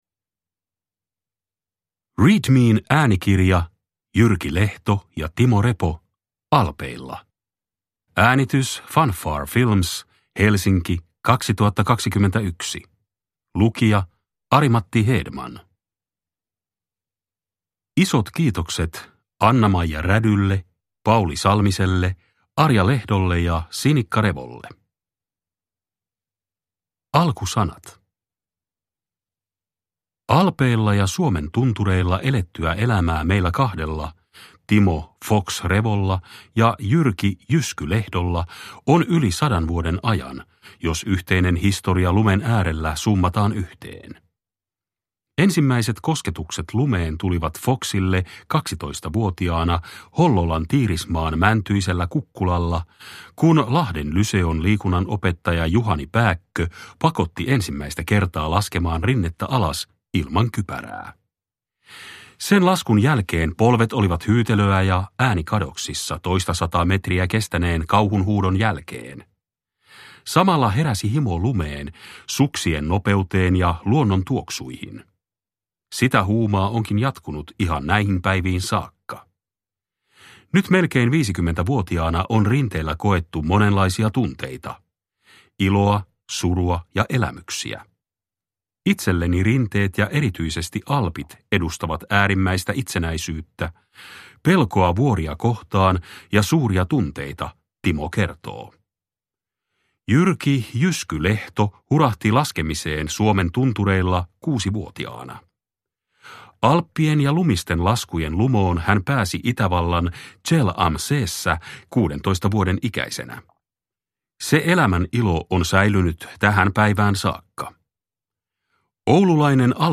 Alpeilla – Ljudbok